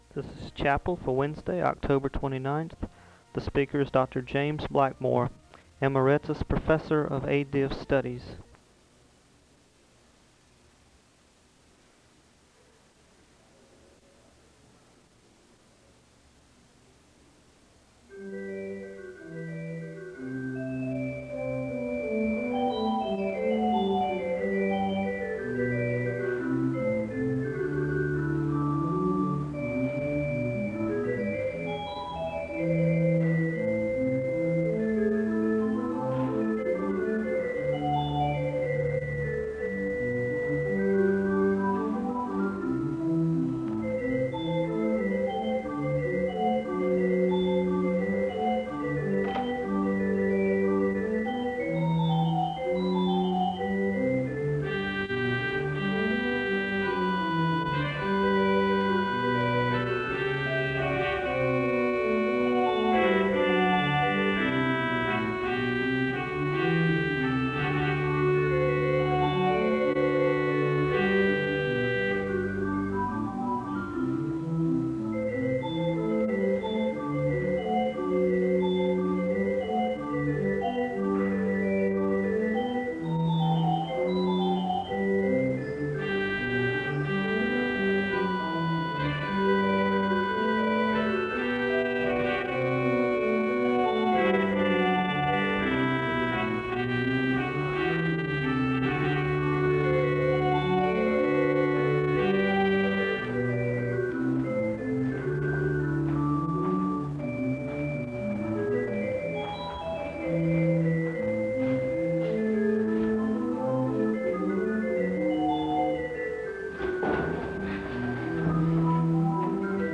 The service begins with organ music (0:00-3:45). There is a Scripture reading from Philippians (3:46-4:49). There is a moment of prayer (4:50-5:27).
There is a benediction to close the service (29:12-29:34).
Location Wake Forest (N.C.)